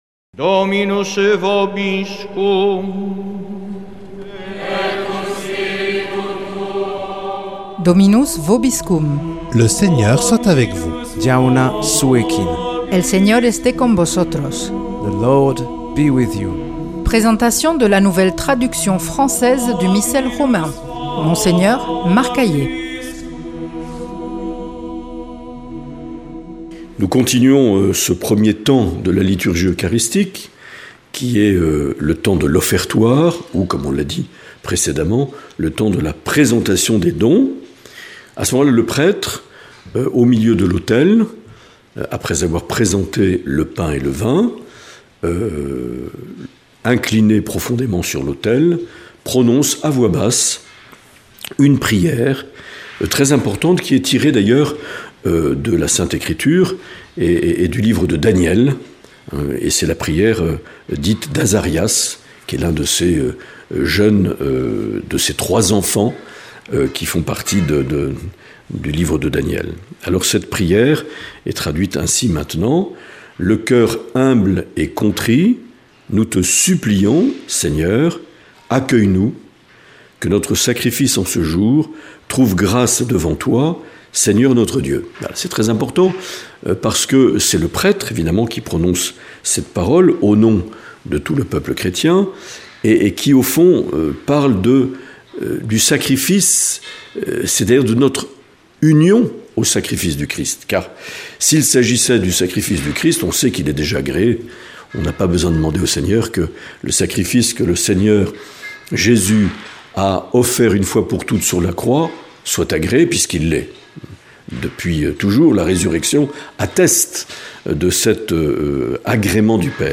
Monseigneur Marc Aillet
Présentateur(trice)